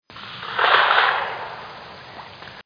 Revontulien ääniä: ratina